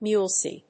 音節mues・li 発音記号・読み方
/mjúːzli(米国英語)/